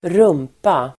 Uttal: [²r'um:pa]